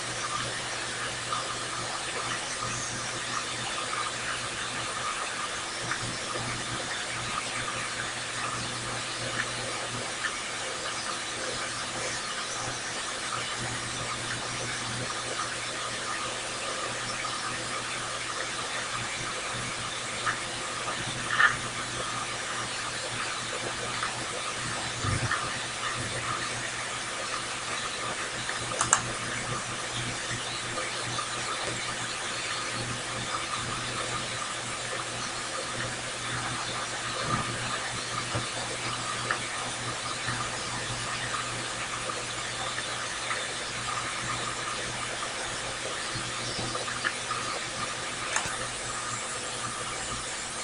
ultrasound recording chatter unedited